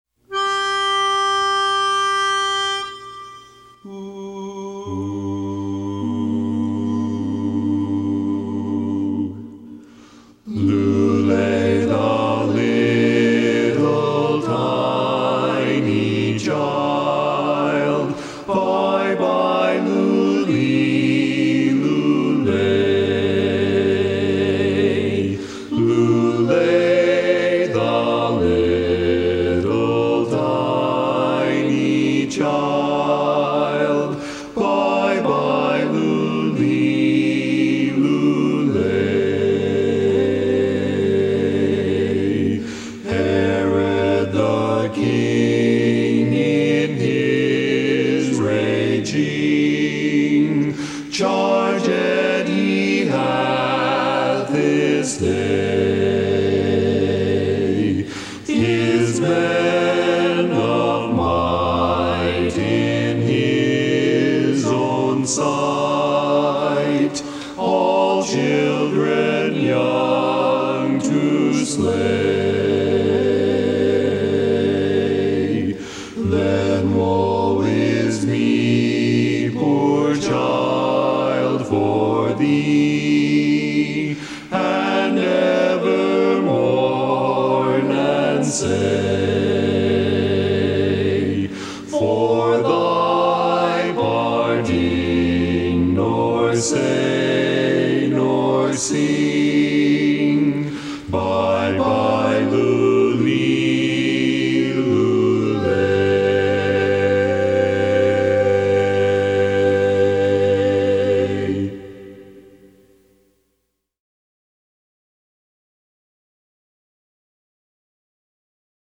Christmas Songs
Barbershop
Bass